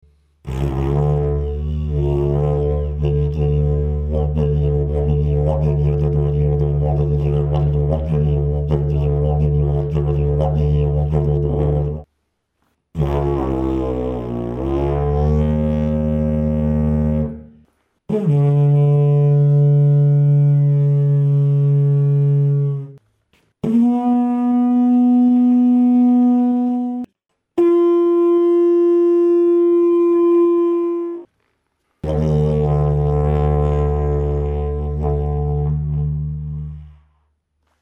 The Dg556 is a medium-sized, slightly conical, narrow didgeridoo with an overblow of approximately an octave. It therefore responds very easily, has a calmer fundamental tone, a less pronounced bass, and can be drawn and modulated across a wide range.
D2-10 (C to D+50) // D3 20 / Bn10 / F-20